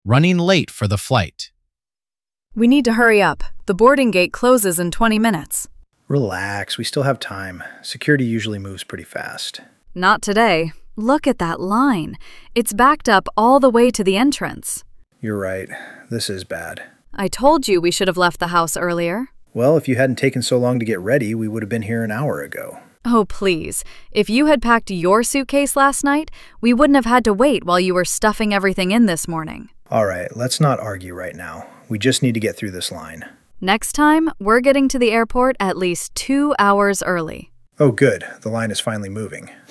Dictation – Running Late for the Flight